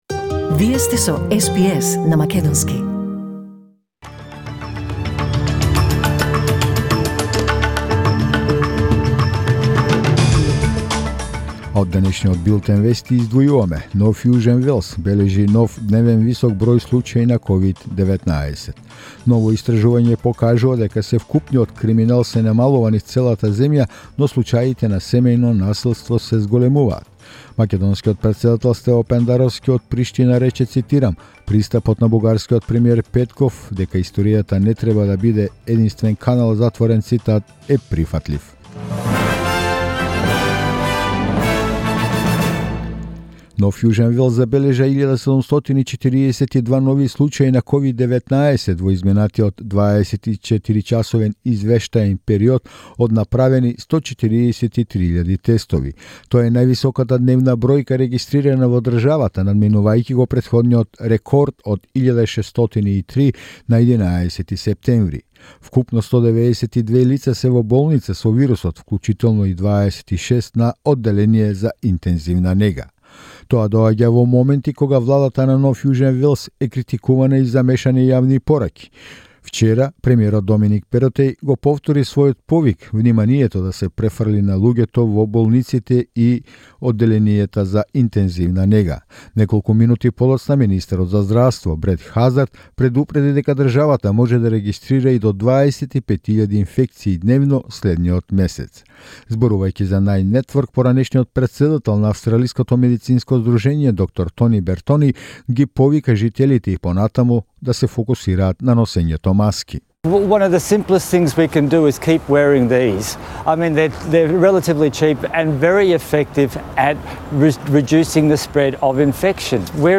SBS News in Macedonian 16 December 2021